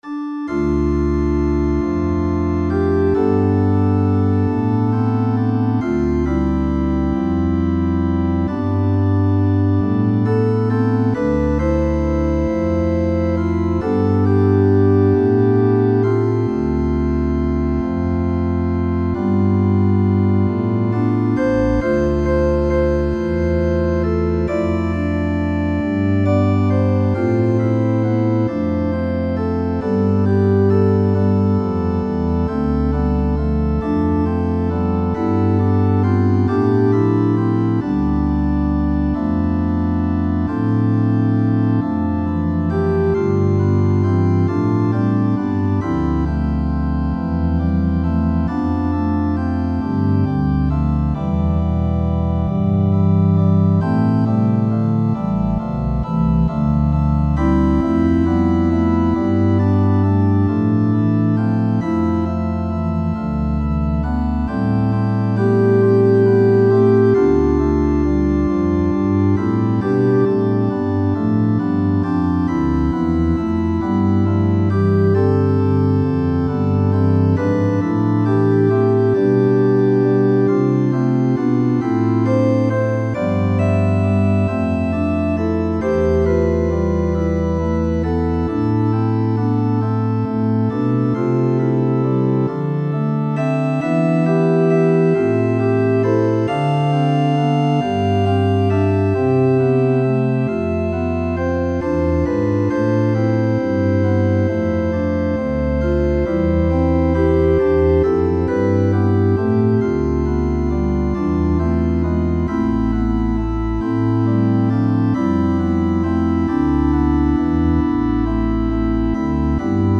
O Heiland, reiß die Himmel auf (Text & Melodie: Friedrich von Spee, 1623) Friedrich von Spees Lied ist ein Kriegsweihnachtslied, der Ruf nach Erlösung in Zeiten tiefer Verzweiflung und bitteren Elends – und immer noch aktuell. In düsterem d−moll erklingt der Orgelchoral voll herber, teilweise aus der Blues-Harmonik entlehnter Dissonanzen, die Melodie erscheint zunehmend gebrochen und auf die vier Stimmen verteilt.